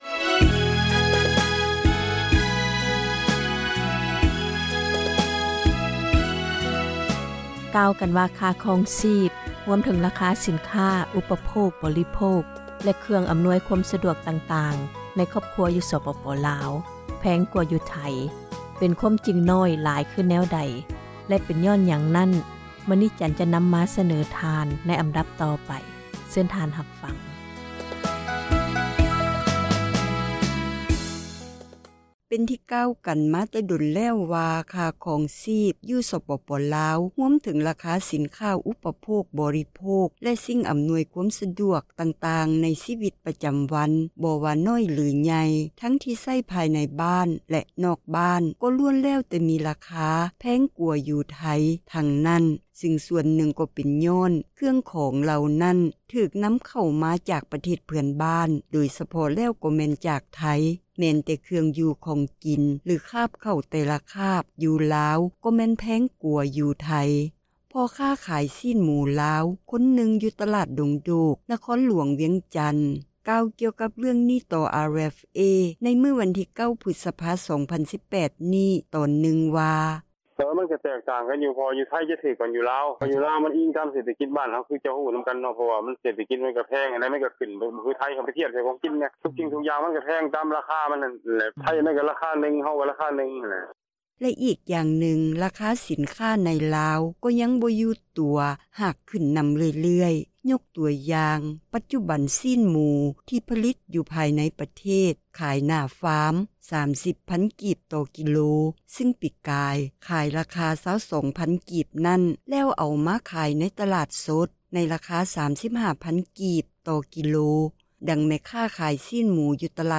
ພໍ່ຄ້າຂາຍຊີ້ນໝູລາວ ຄົນນຶ່ງຢູ່ຕລາດ ດົງໂດກ ນະຄອນຫລວງວຽງຈັນ ກ່າວກ່ຽວກັບເຣື່ອງນີ້ ຕໍ່ RFA ໃນມື້ວັນທີ 9 ພຶສພາ 2018 ນີ້ວ່າ: